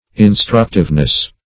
instructiveness - definition of instructiveness - synonyms, pronunciation, spelling from Free Dictionary
-- In*struct"ive*ly, adv. -- In*struct"ive*ness, n.